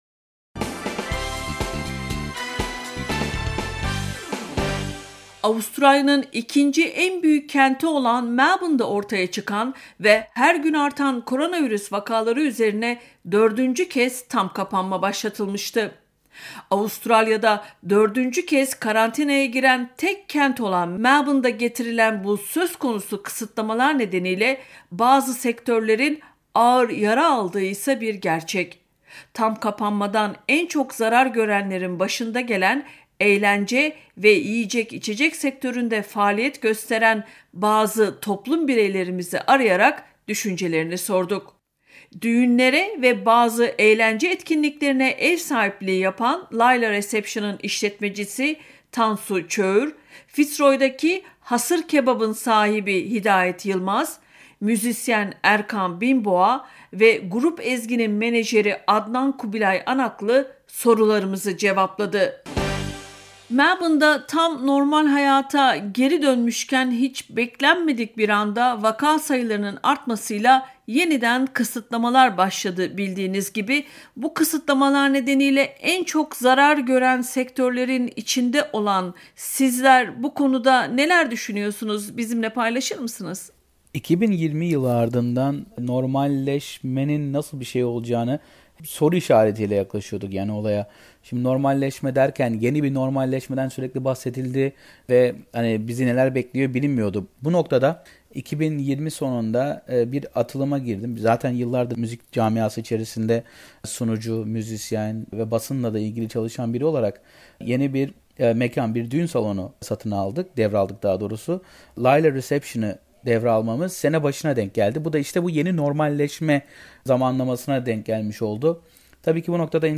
Avustralya’da dördüncü kez karantinaya giren tek kent olan Melbourne’da başlatılan kısıtlamalar nedeniyle en çok zarar görenlerin başında gelen eğlence ve yiyecek içecek sektöründe faaliyet gösteren bazı toplum bireylerimizi arayarak düşüncelerini sorduk.